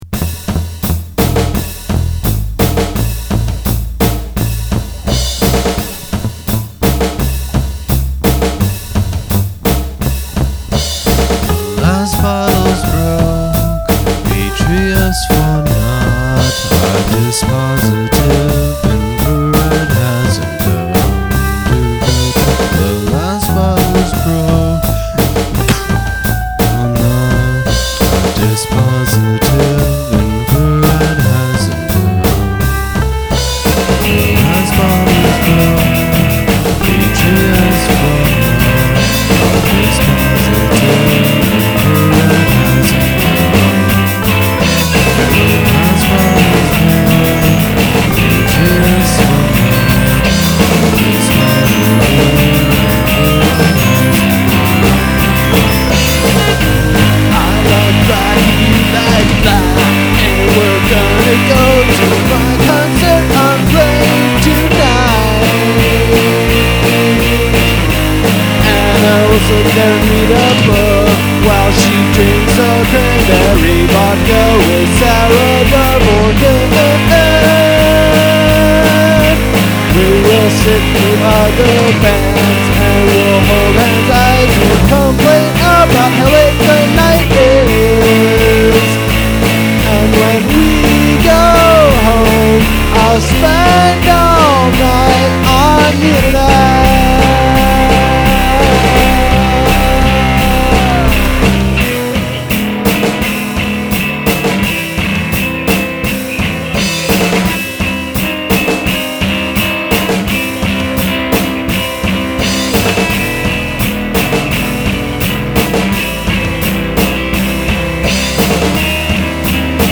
These are all just "test" lyrics while trying to figure out a melody
Verse: E7, Am7, [some Cm variant],C
Chorus: Ab, G, C, B, Bb
Unfinished, so still stuck at A/B/A/B
I was in a counterpoint class at the time, and wrote out the verse melody in a sheetmusic/MIDI program first (it performs the piano line).
In hindsight, I really like it because it just sounds like some idiot singing along to something.